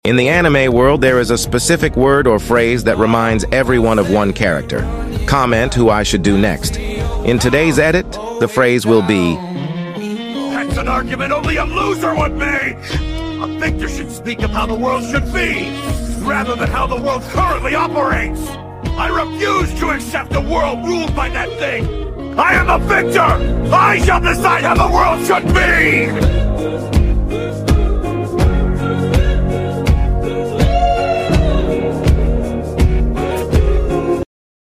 Aizen speech to Kisuke